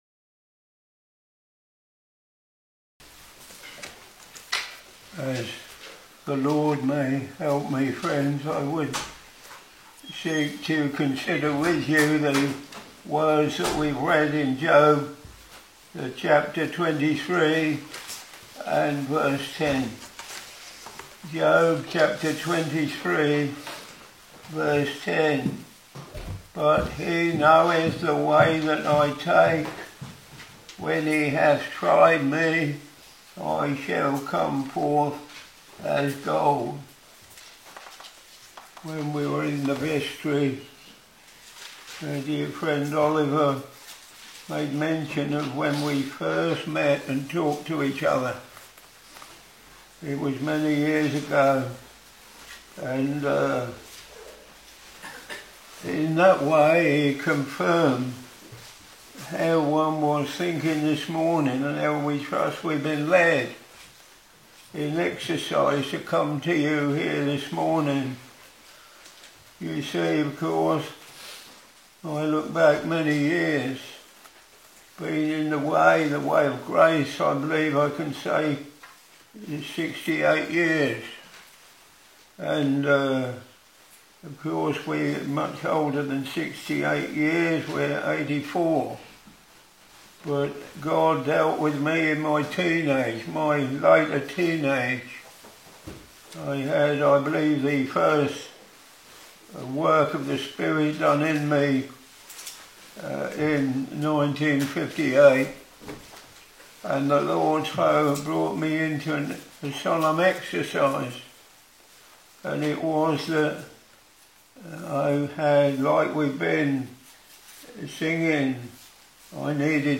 Back to Sermons Job Ch.23 v.10 But he knoweth the way that I take: when he hath tried me, I shall come forth as gold.